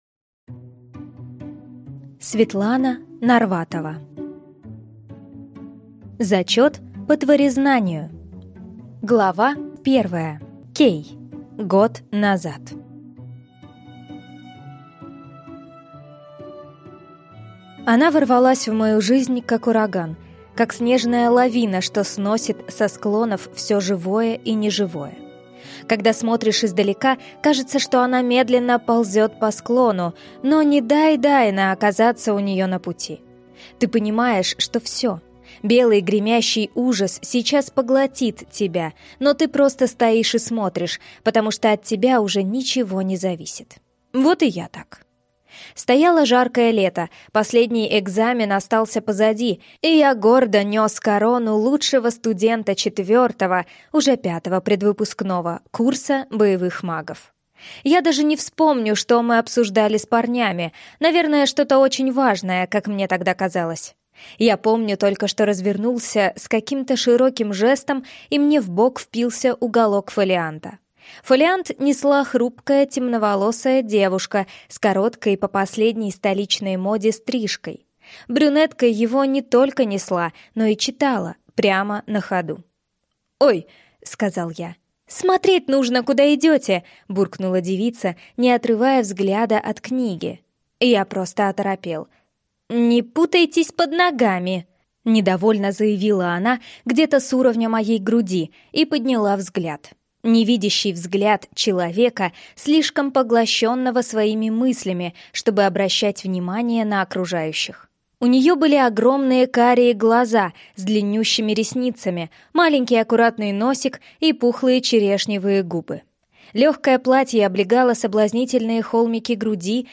Аудиокнига Зачет по тварезнанию | Библиотека аудиокниг
Прослушать и бесплатно скачать фрагмент аудиокниги